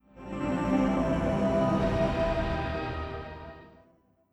OS3 Warp 3.0 Startup.wav